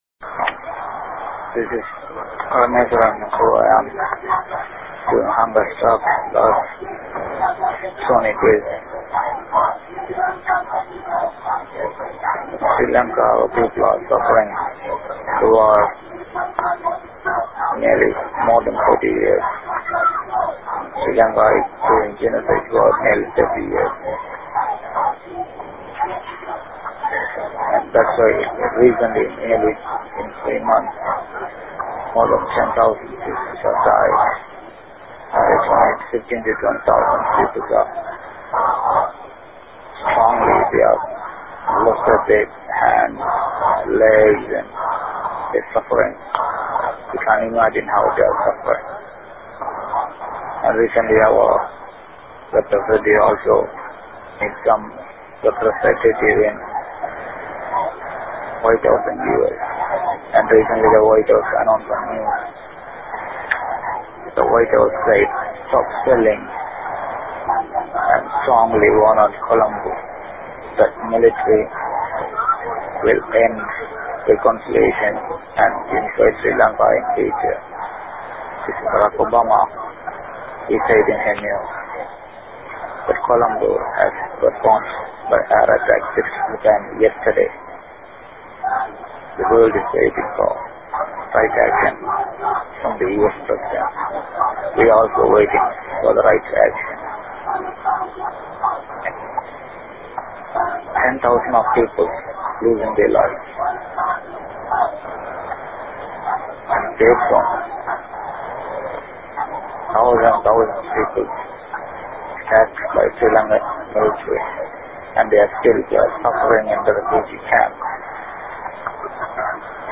In a faint voice barely audible